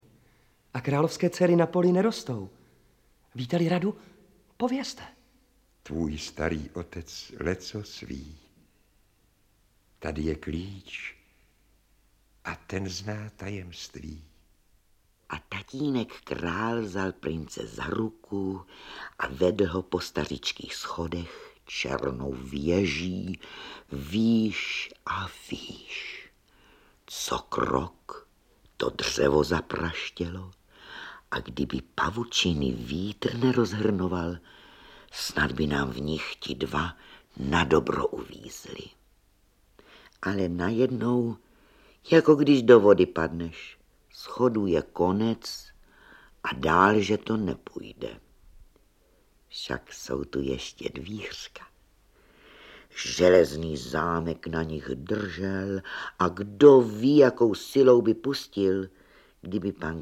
Audiobook
Read: Gabriela Vránová